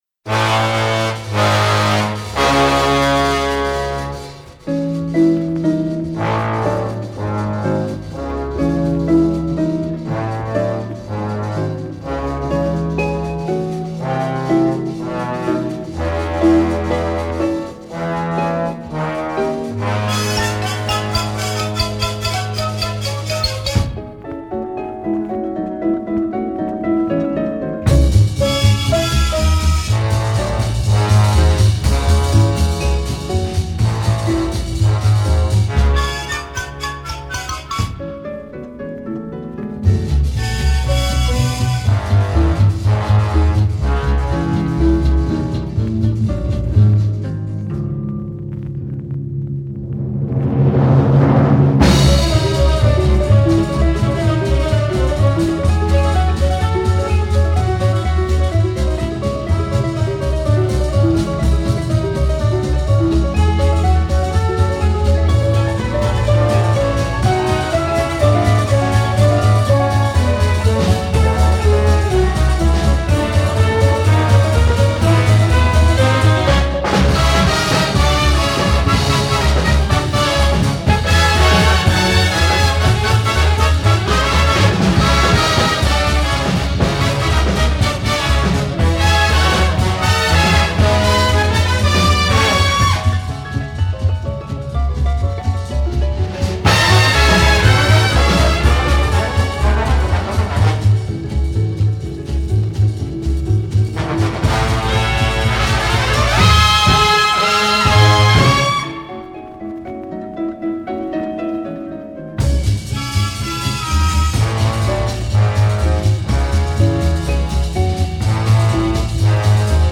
бит-версия.